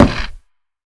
Minecraft Version Minecraft Version 1.21.5 Latest Release | Latest Snapshot 1.21.5 / assets / minecraft / sounds / block / creaking_heart / hit / creaking_heart_hit4.ogg Compare With Compare With Latest Release | Latest Snapshot
creaking_heart_hit4.ogg